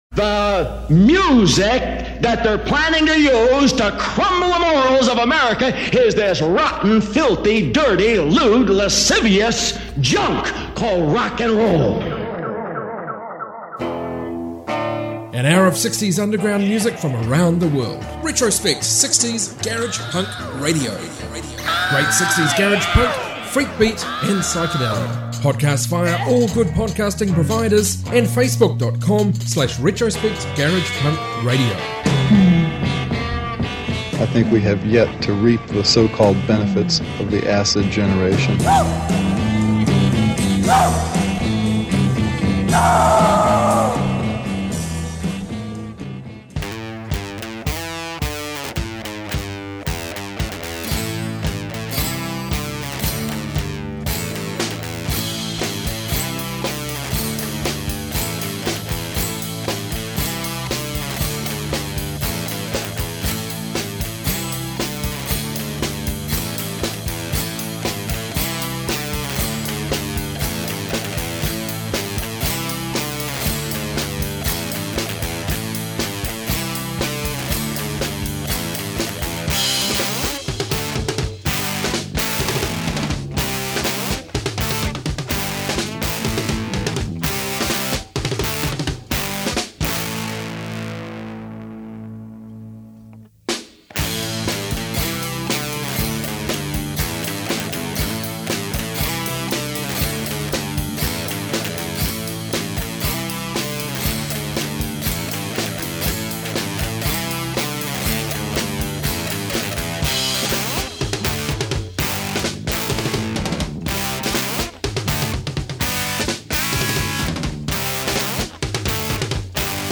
60s garage, freakbeat & psych